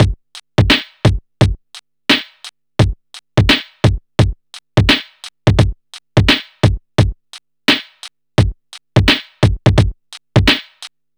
Free breakbeat - kick tuned to the G note. Loudest frequency: 1514Hz
86-bpm-drum-loop-g-key-8QM.wav